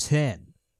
TS Sounds / Voices / Male